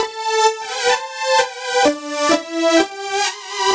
Index of /musicradar/french-house-chillout-samples/128bpm/Instruments
FHC_Pad B_128-A.wav